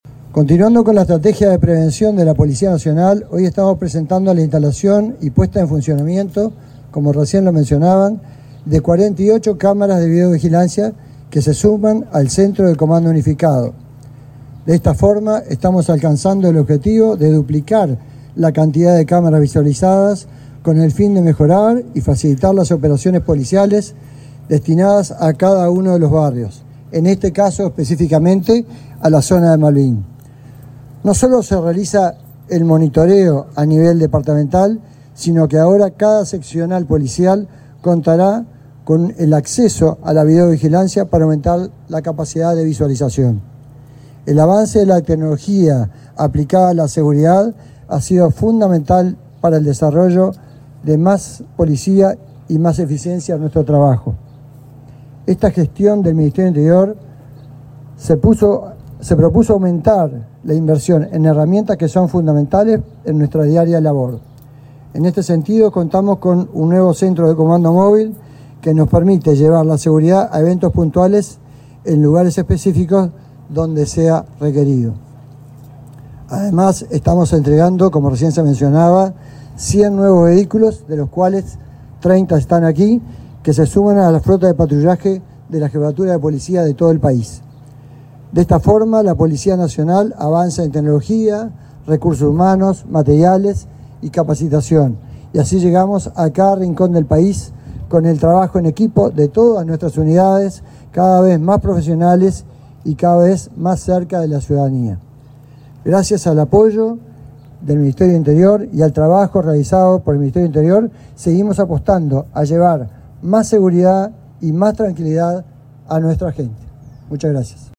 Palabras del subdirector de la Policía Nacional, Johnny Diego
Palabras del subdirector de la Policía Nacional, Johnny Diego 12/01/2024 Compartir Facebook X Copiar enlace WhatsApp LinkedIn El subdirector de la Policía Nacional, Johnny Diego, efectuó una oratoria en el acto de presentación de las cámaras de videovigilancia instaladas en el barrio capitalino de Malvín.